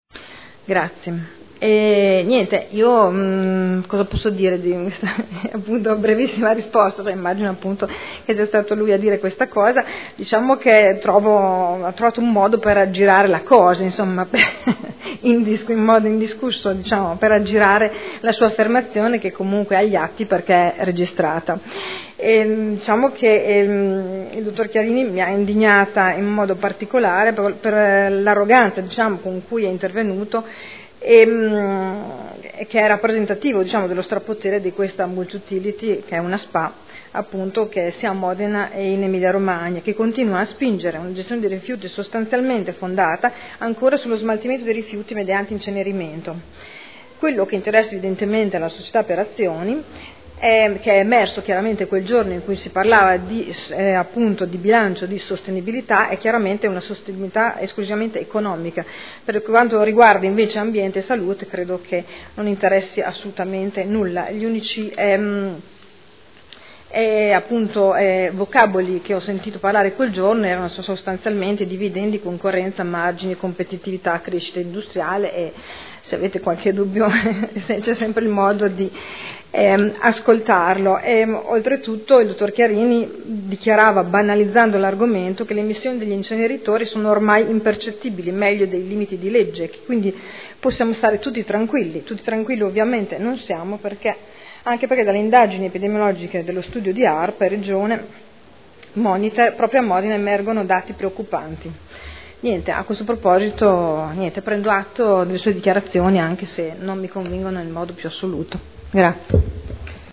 Seduta del 04/03/2013. Replica a risposta Ass. Arletti.